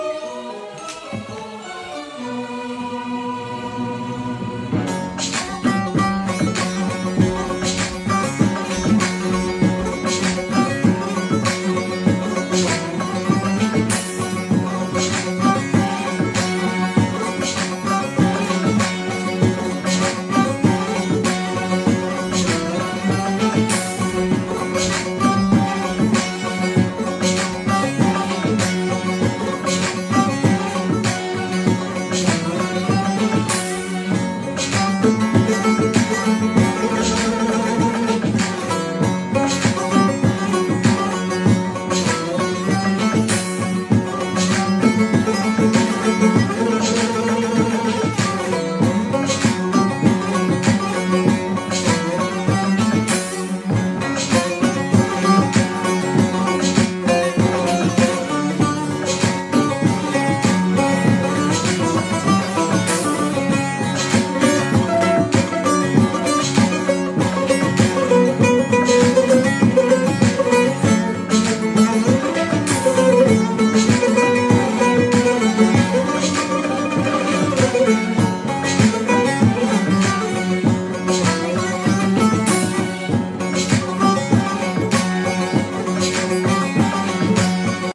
زنگ خور گنگ خفن